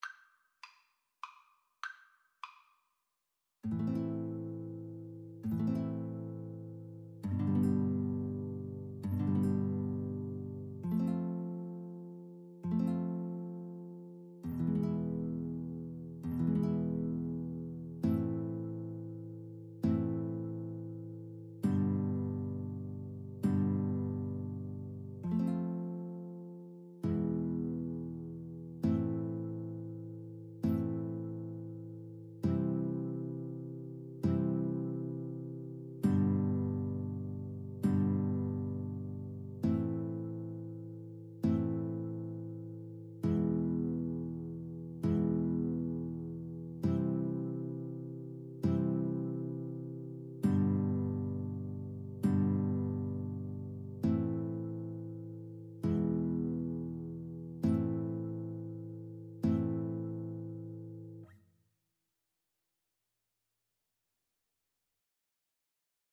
Free Sheet music for Violin-Guitar Duet
A minor (Sounding Pitch) (View more A minor Music for Violin-Guitar Duet )
3/4 (View more 3/4 Music)
Traditional (View more Traditional Violin-Guitar Duet Music)